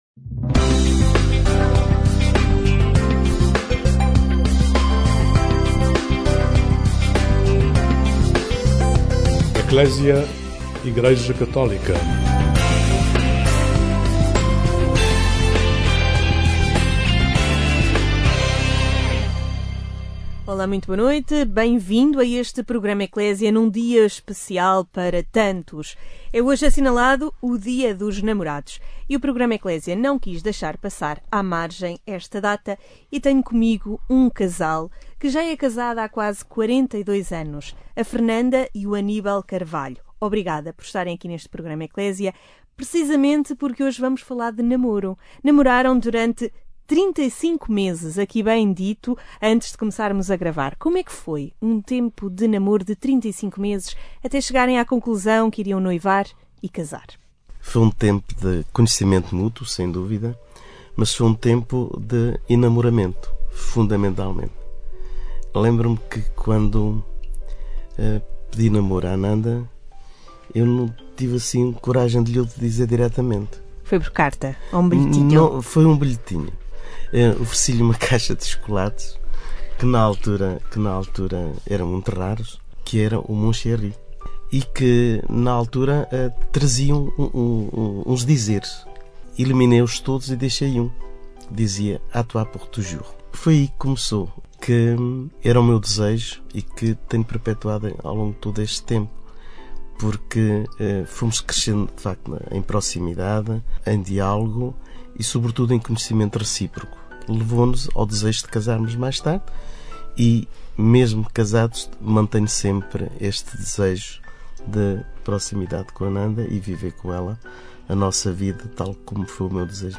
Numa conversa informal houve tempo para perceber as descobertas do tempo de namoro, a decisão de casar e o namoro permanente entre o casal.